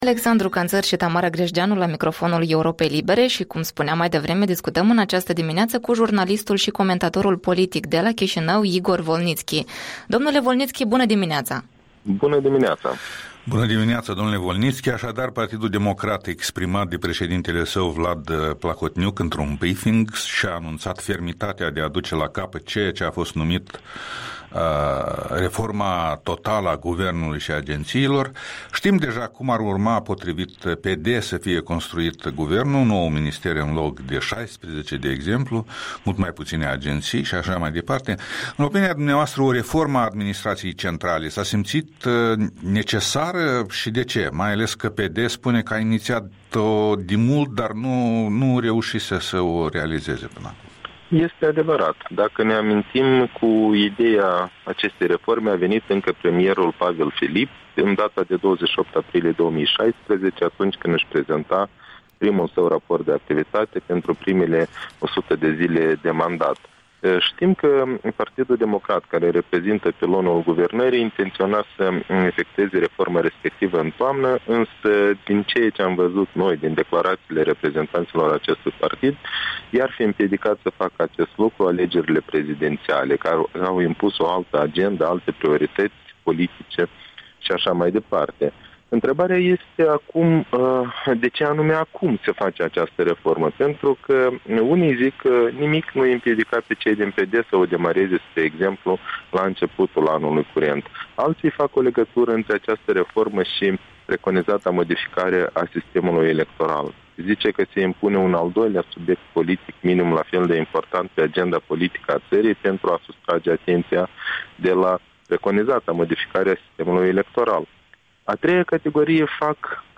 Interviul dimineții cu analistul politic de la Chișinău.